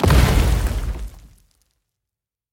explosion.ogg